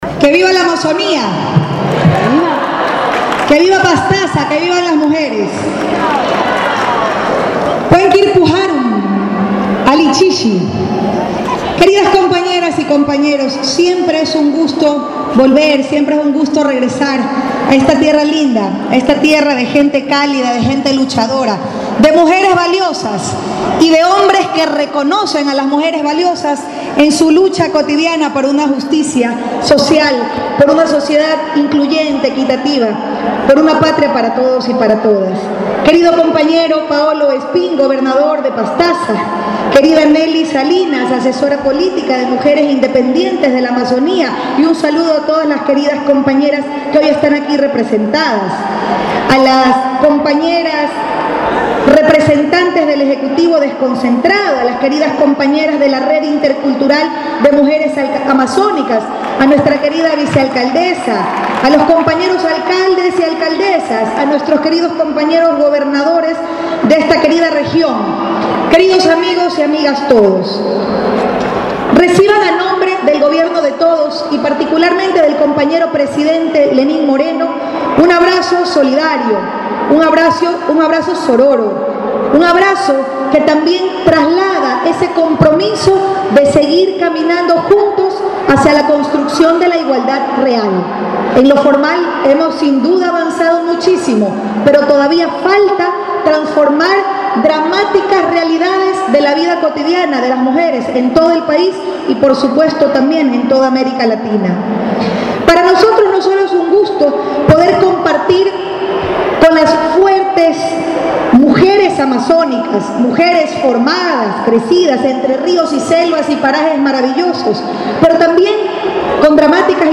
Con un coliseo que no se lleno, cerca de las 15h45 llego la Vicepresidenta de la República enc. Maria Alejandra Vicuña, quien dio su saludo a las mujeres amazónicas que se dieron cita en el lugar e invito a estar unidas mas que nunca, también a apoyar la Consulta Popula. Hizo una retrospectiva del maltrato de la mujer y contra eso hay que luchar, manifestó.
A continuación parte de su saludo en este evnto, en el audio
maria-alejandra-vicuña-vicepresiudenta.mp3